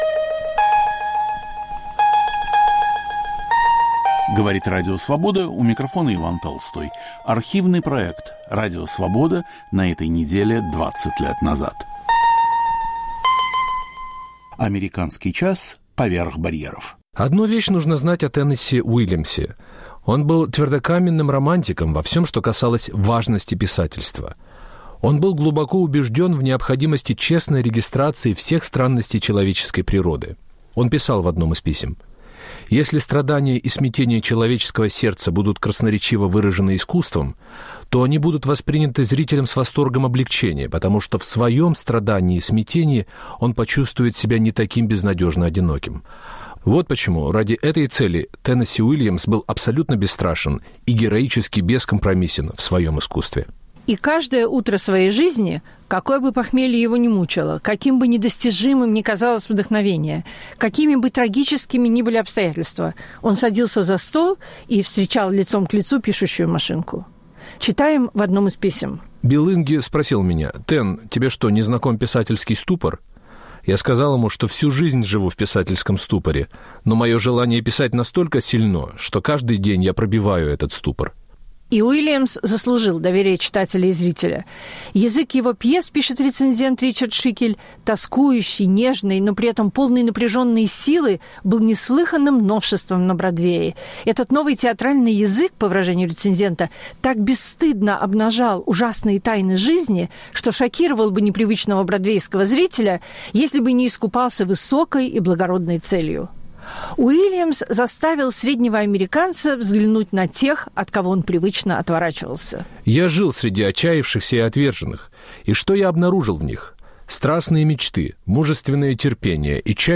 Гость - поэт Лев Лосев. "Мелинда и Мелинда" - новый фильм Вуди Аллена.
Подготовил и ведет передачу Александр Генис.